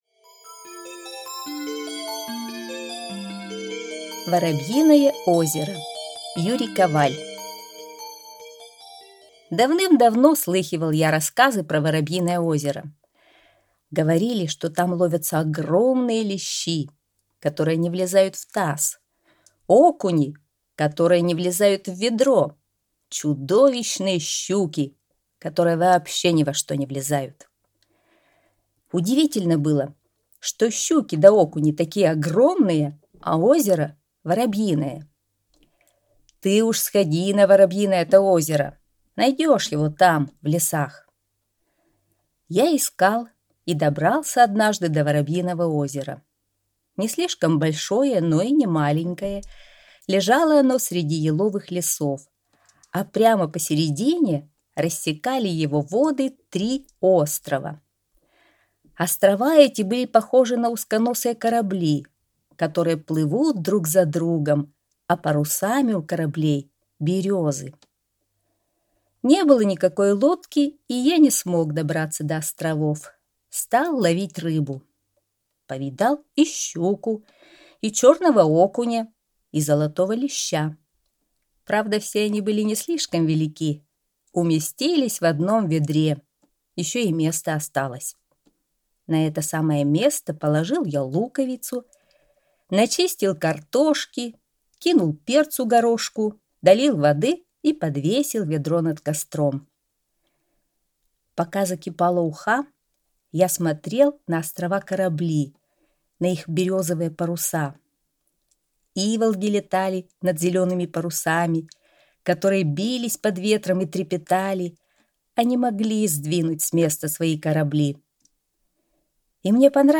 Аудиорассказ «Воробьиное озеро»